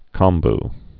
(kŏmb)